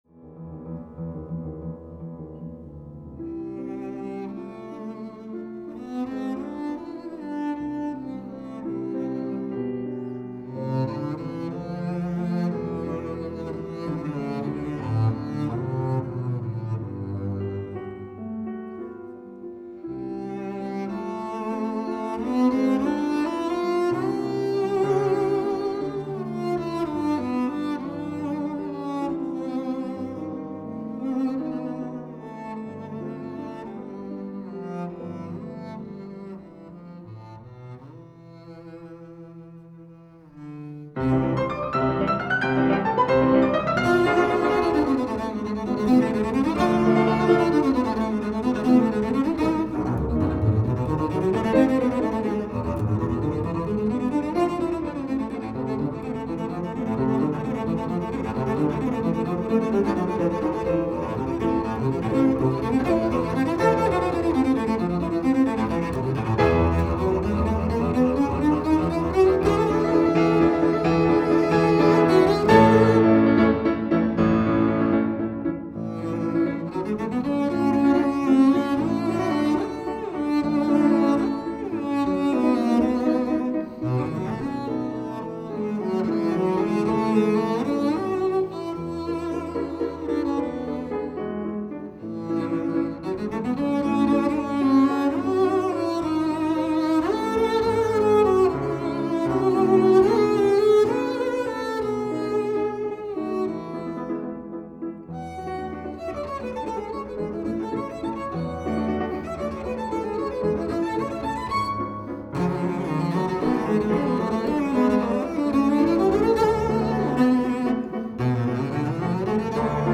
DATA FORMAT  |  Audio as a high quality stereo MP3 file
double bass
piano